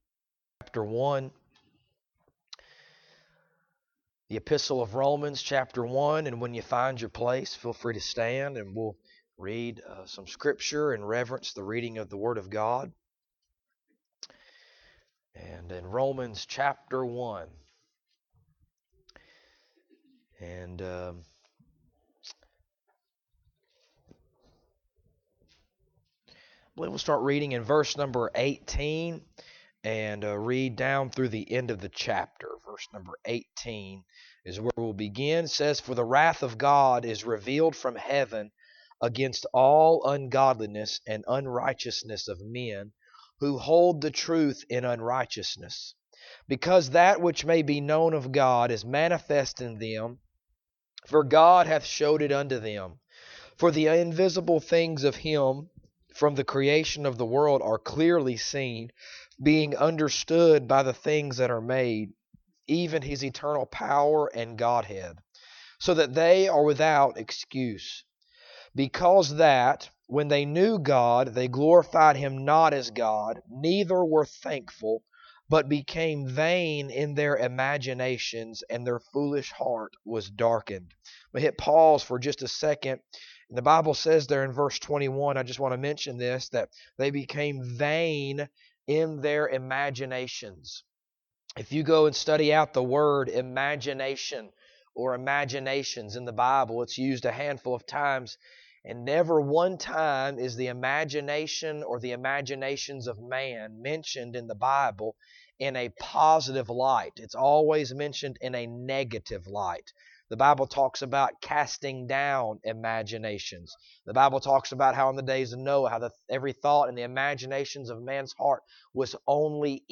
Romans Passage: Romans 1:18-32 Service Type: Sunday Evening Topics